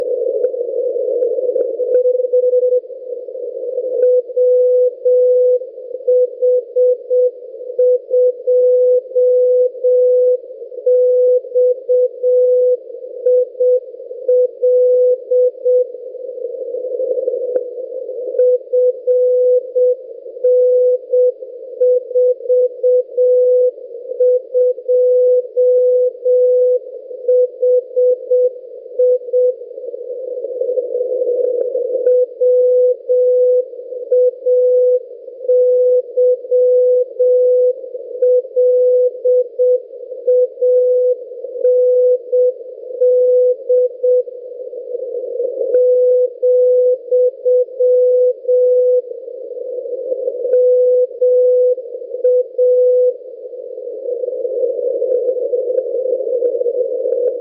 This was recorded during the day. The propagation mode is ground wave at a distance of 233 miles.
Practical antennas at this frequency (137 kHz) are not very efficient.